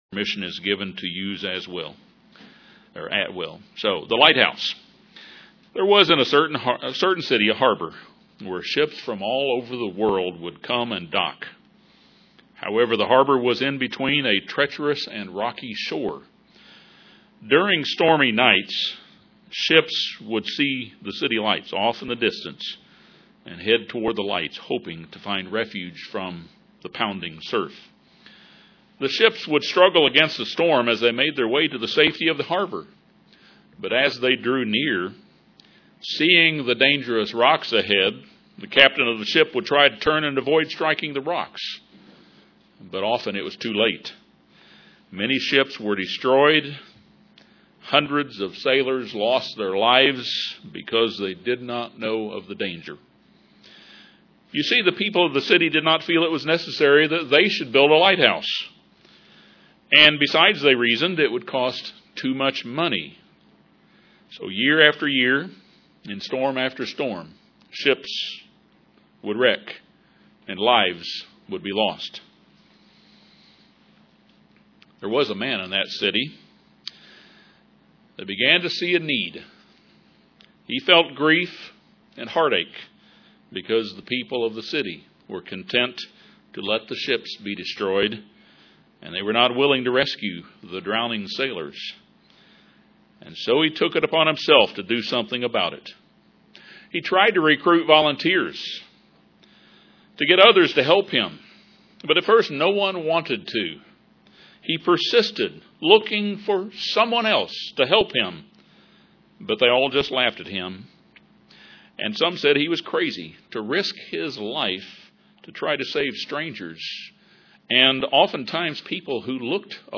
Our need to watch and be ready for prophetic events moving forward UCG Sermon Transcript This transcript was generated by AI and may contain errors.